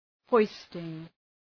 Προφορά
{‘hɔıstıŋ}